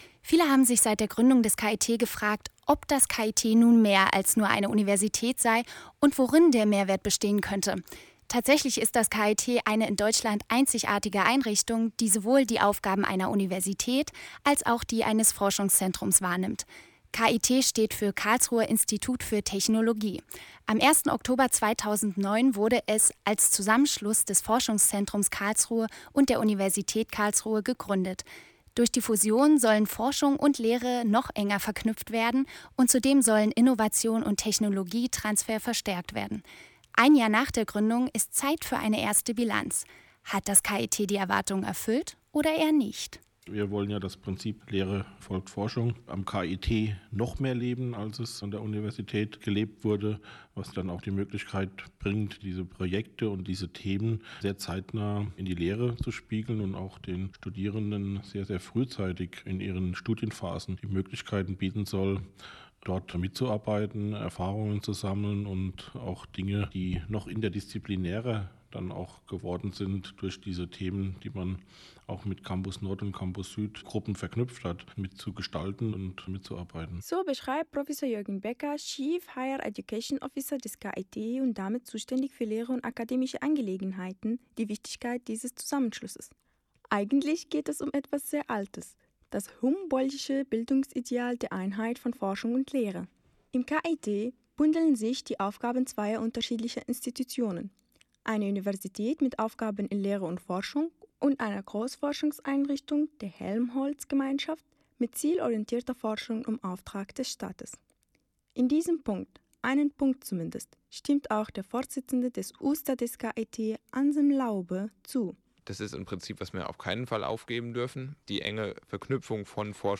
Was hat das KIT für die Lehre gebracht? : Versuch einer Zwischenbilanz ; Beitrag bei Radio KIT am 28.04.2011